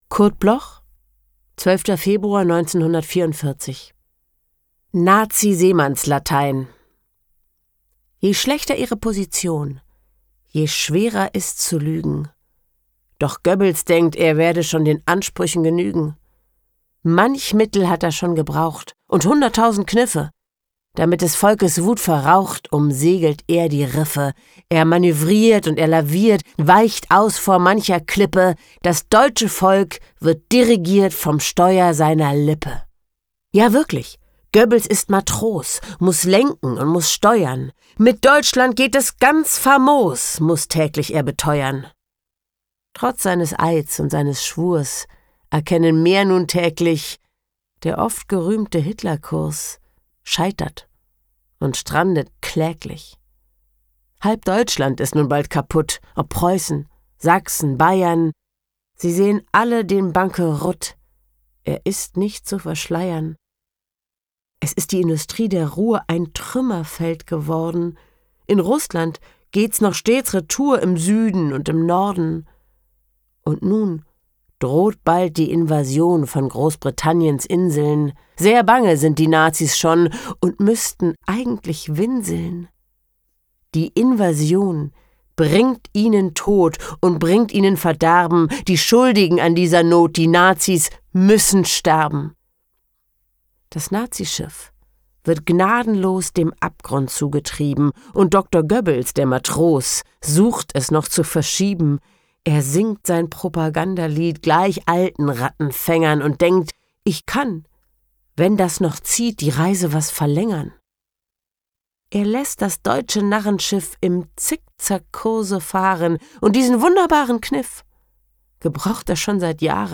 Aufnahme: der apparat, Berlin · Bearbeitung: Kristen & Schmidt, Wiesbaden